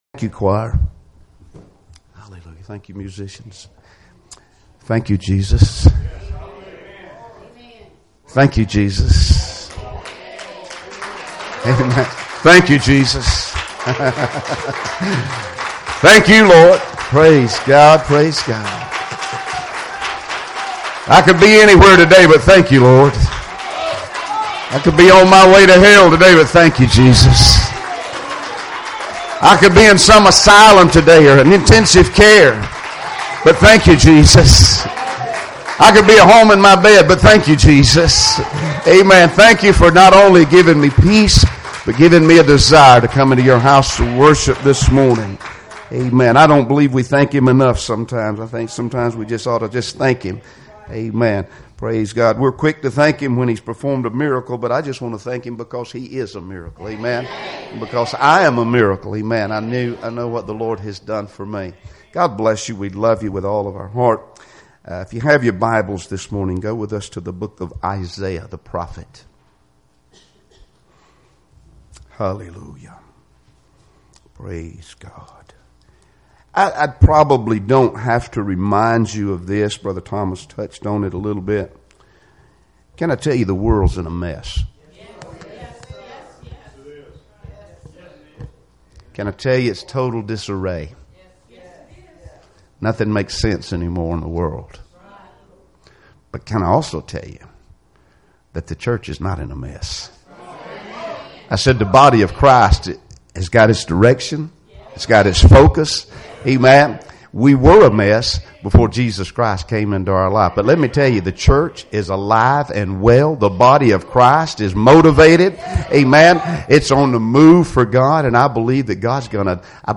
Isaiah 1:2-9 Service Type: Sunday Morning Services Topics